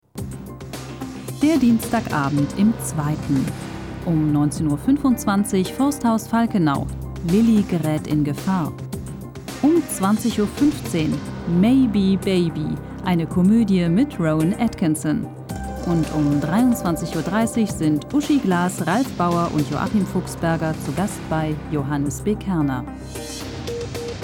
Typ: warme Stimme, sehr wandlungsfähig: von seriös, frisch bis sexy.
Profi-Sprecherin.
Sprechprobe: eLearning (Muttersprache):